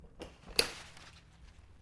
Звуки зонта
зонтик сложили